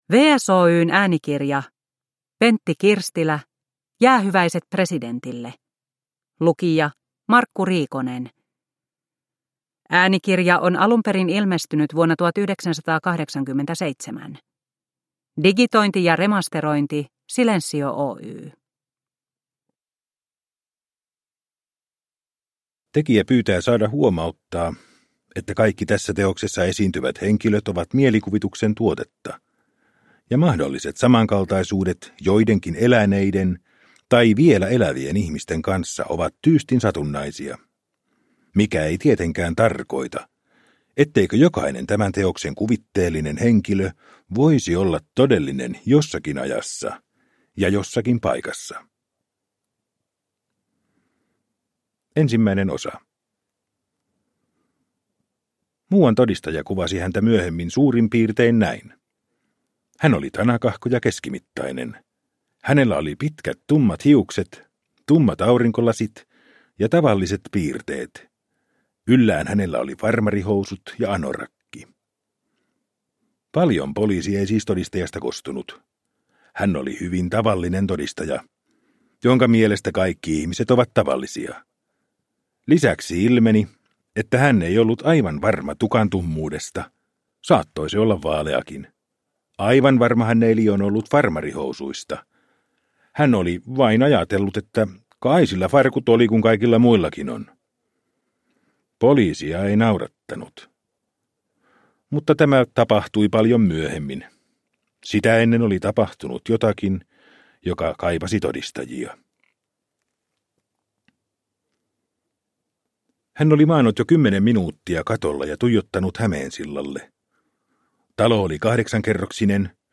Jäähyväiset presidentille – Ljudbok – Laddas ner